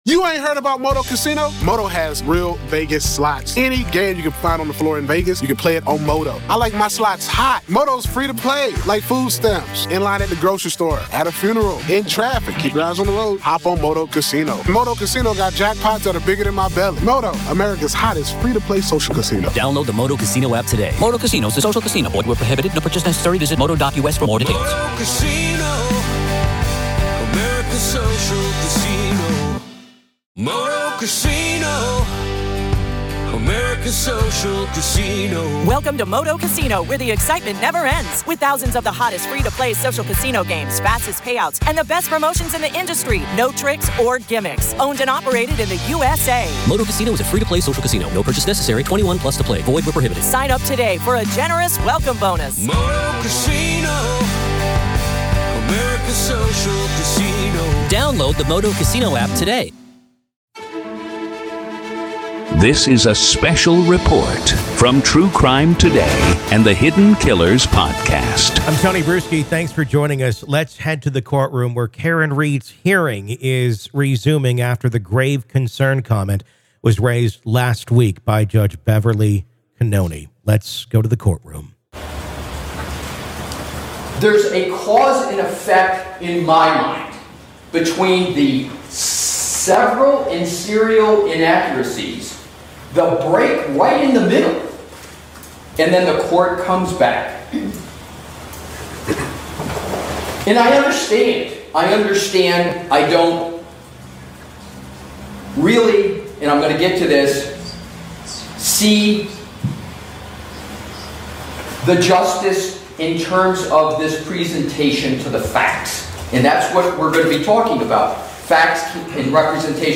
RAW COURT AUDIO: Karen Read’s Legal Battle Intensifies as Courtroom Tensions Rise PART 3